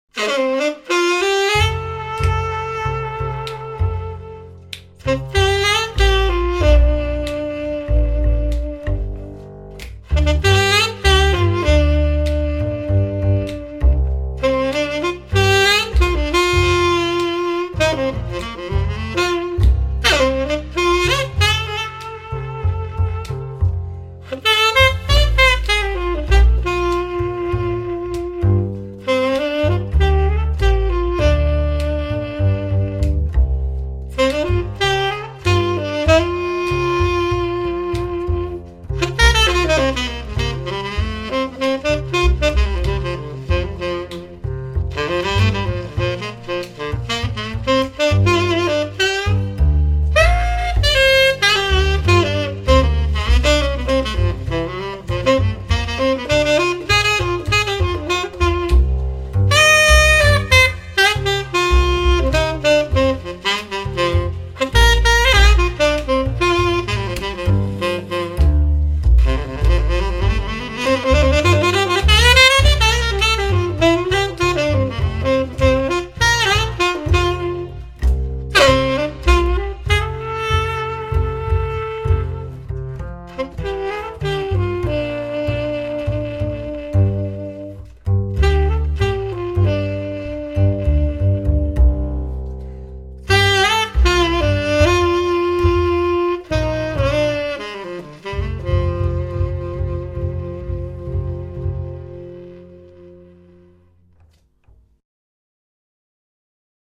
„Groovin‘ Music“ für Ihren exklusiven Event!
DAS Acoustic Lounge Duo